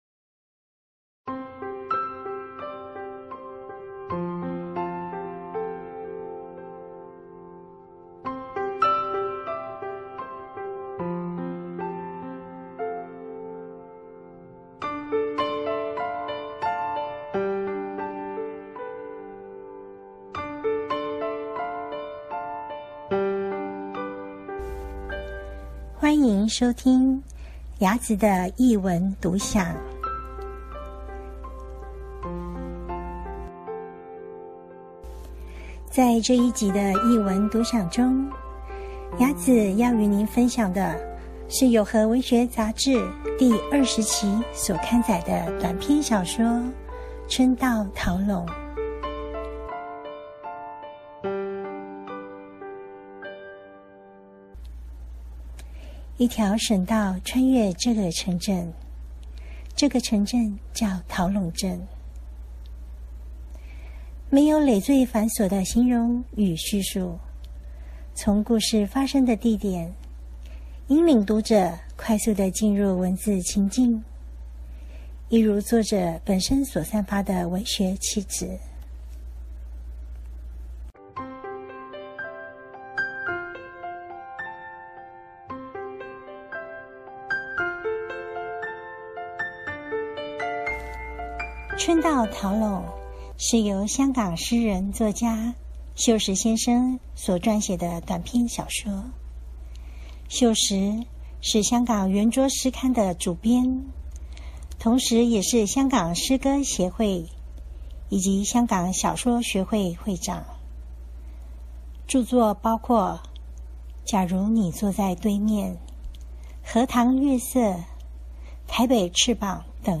藝文電台
期望以聲音傳達文學之美